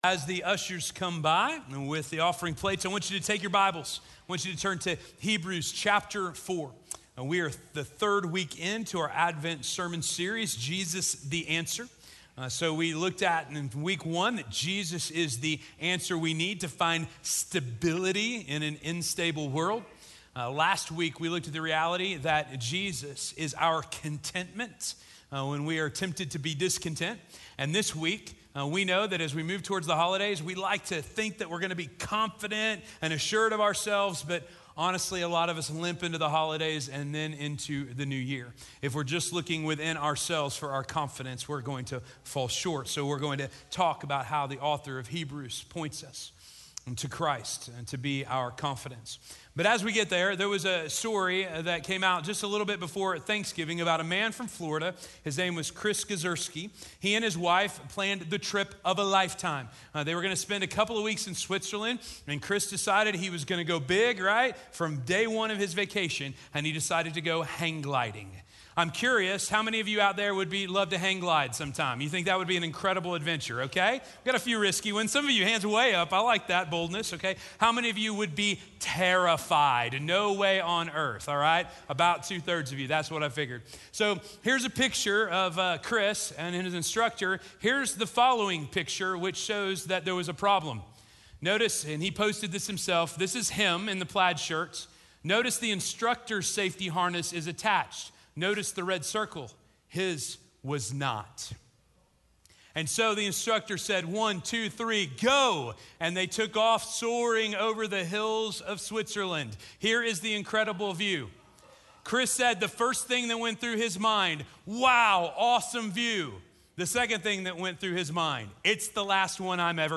We can Know...Confidence - Sermon - Station Hill